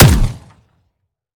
weap_romeo870_sup_plr_01.ogg